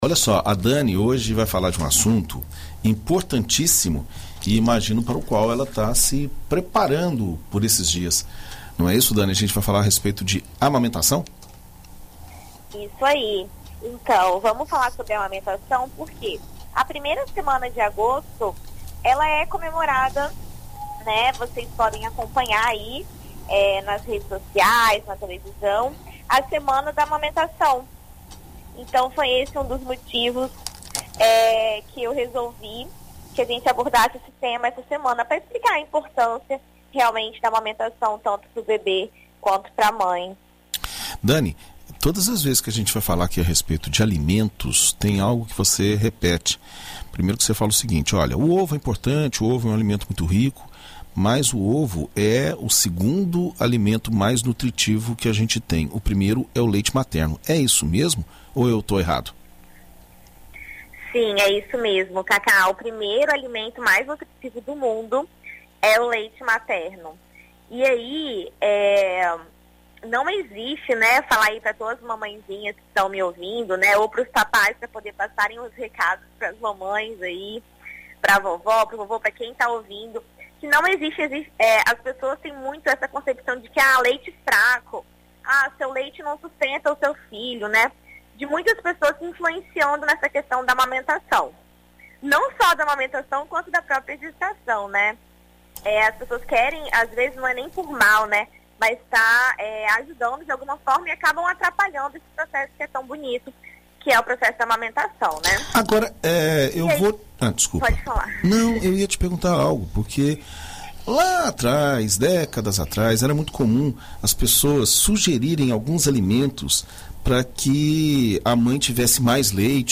Na coluna Viver Bem desta quarta-feira (04), na BandNews FM Espírito Santo